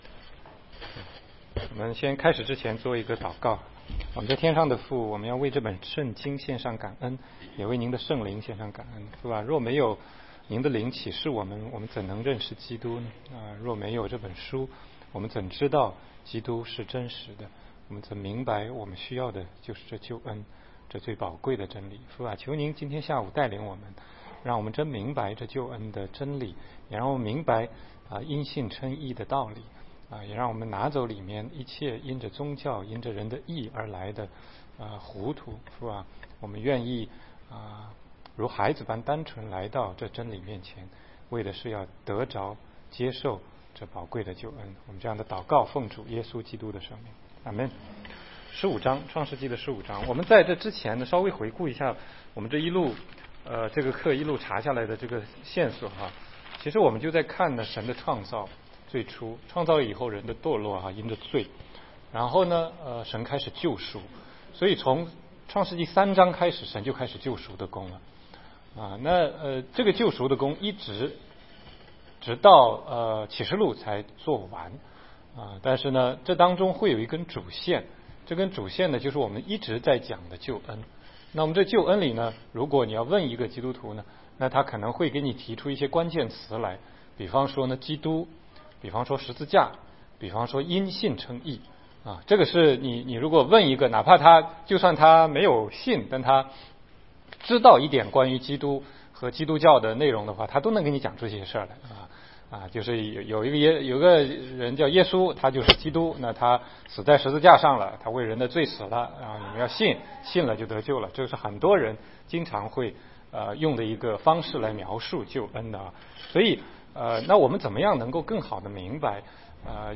16街讲道录音 - 神与亚伯拉罕立约